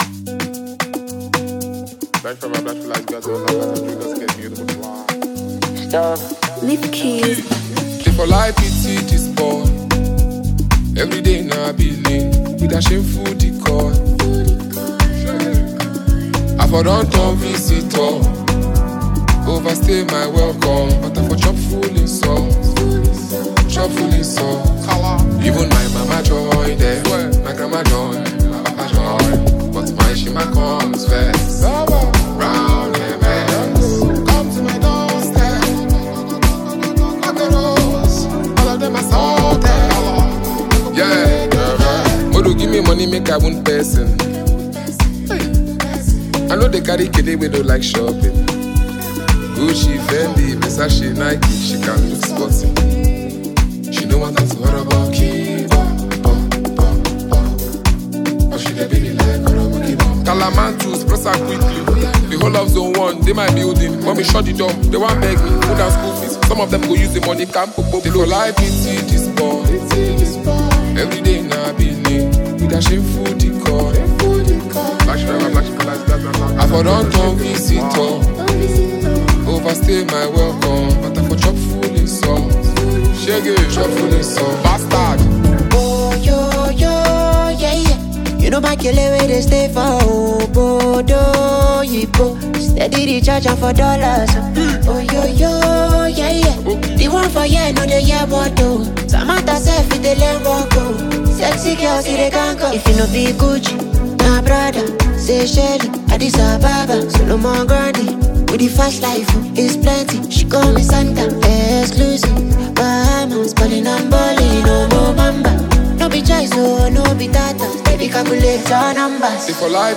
IGBO rapper, Songwriter and performer